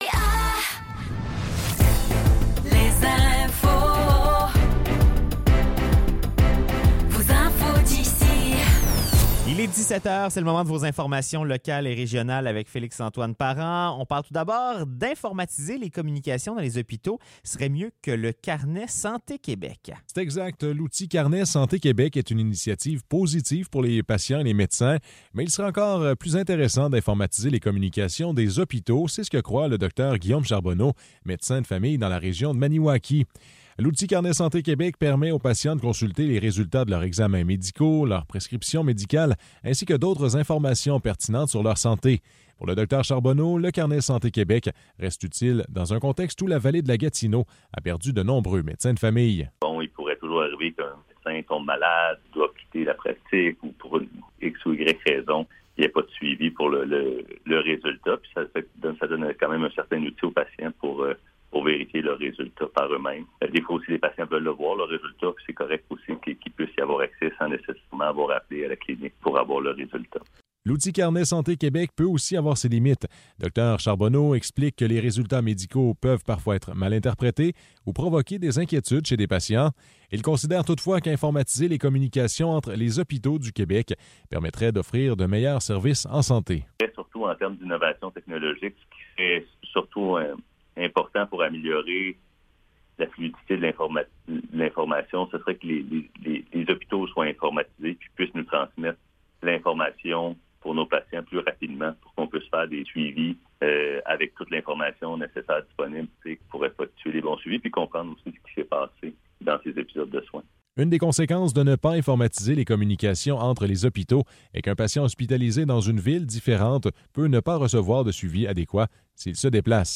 Nouvelles locales - 7 février 2024 - 17 h